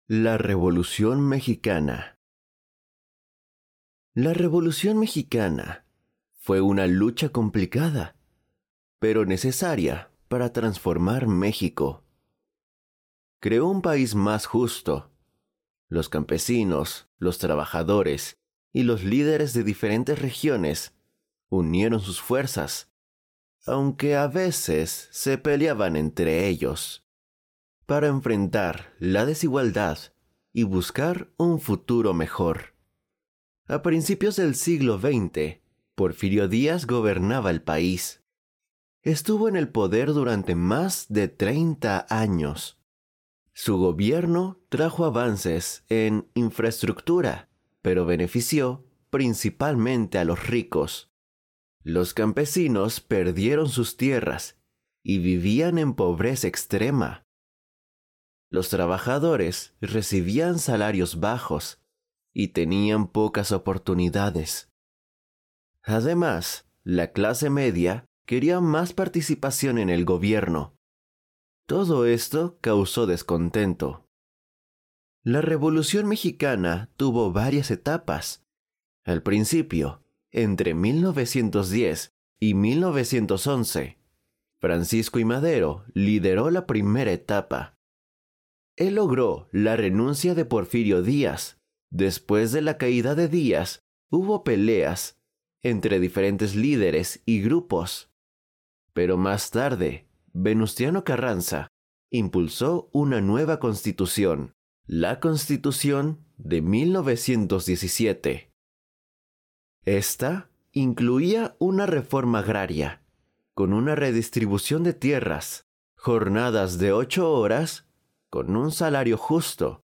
Spanish online reading and listening practice – level A2
audio by a professional Mexican voice actor.